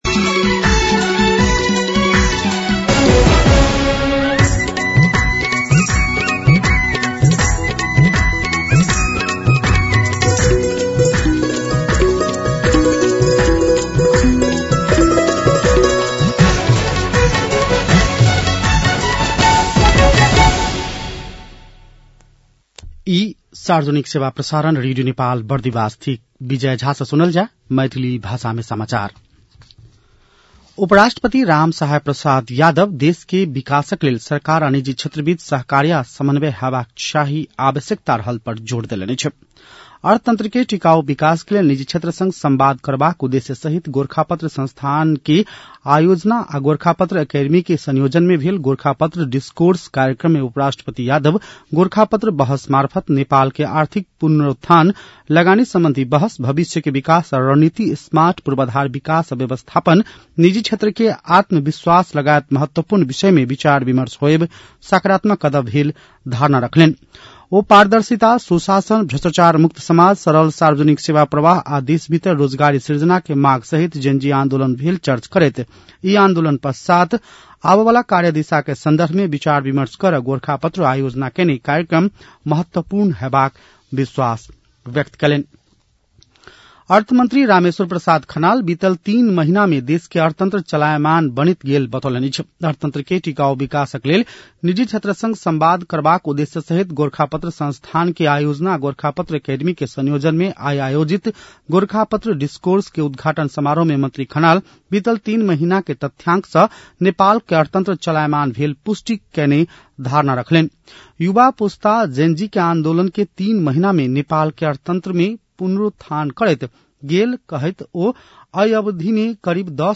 मैथिली भाषामा समाचार : ३ पुष , २०८२
6-pm-maithali-news-9-3.mp3